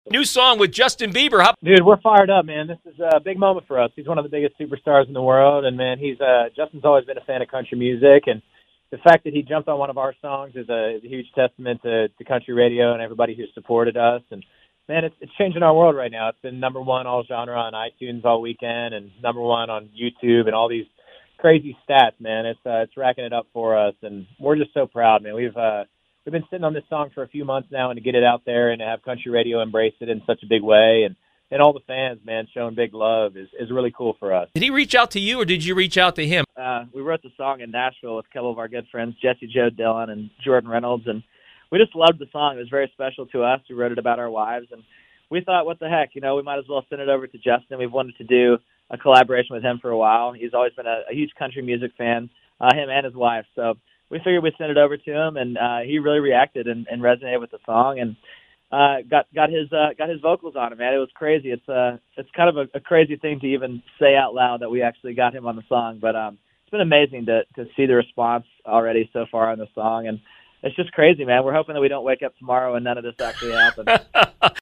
Dan + Shay interview Dan talks Bieber